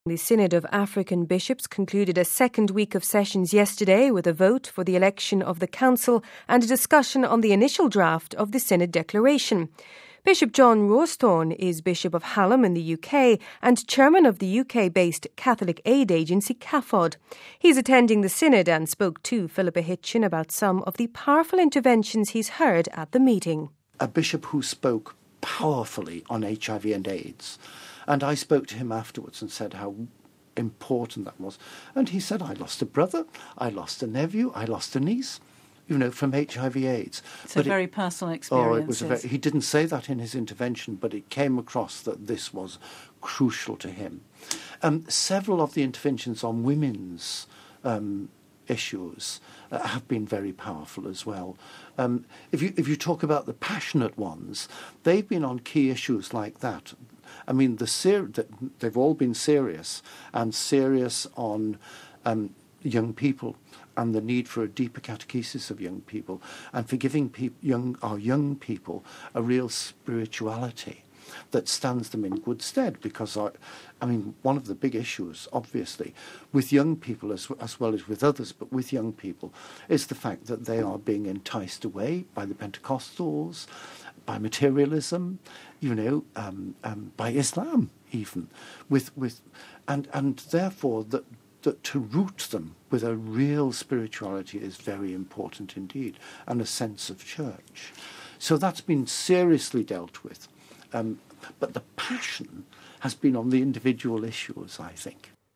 Bishop John RAWSTHORNE is Bishop of Hallam in the UK and Chairman of the UK based Catholic aid agency CAFOD.